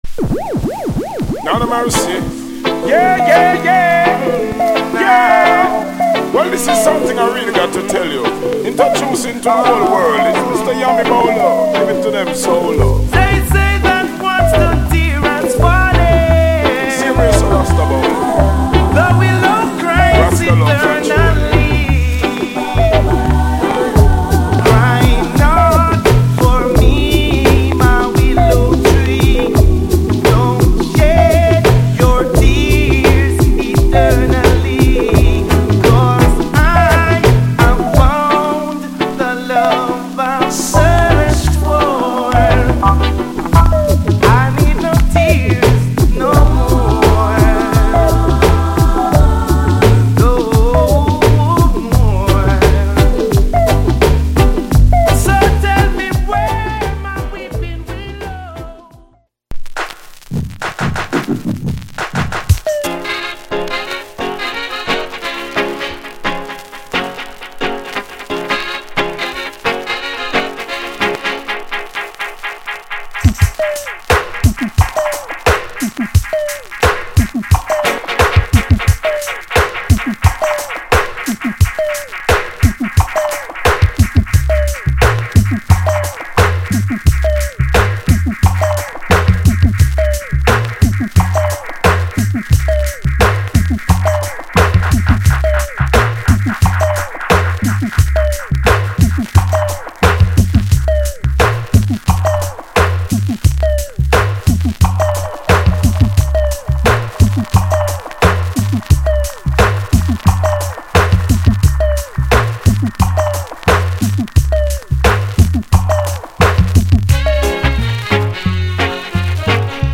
** ジャマイカの再発盤特有のプレスノイズあり。